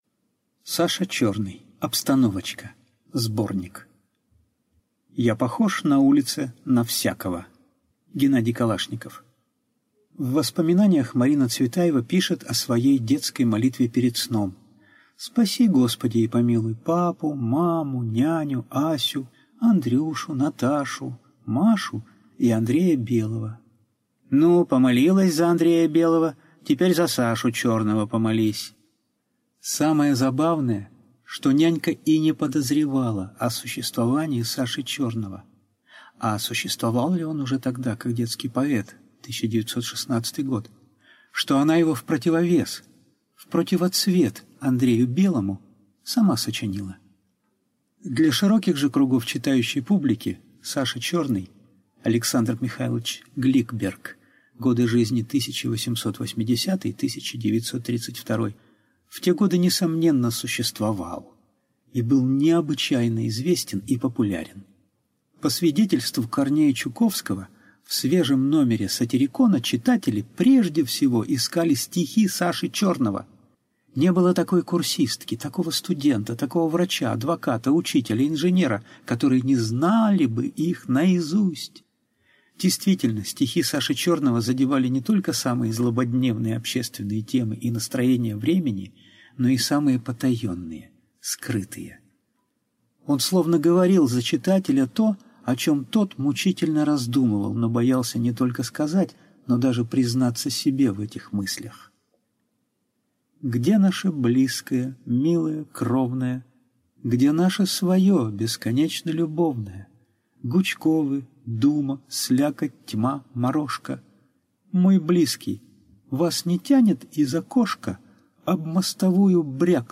Аудиокнига Обстановочка (сборник) | Библиотека аудиокниг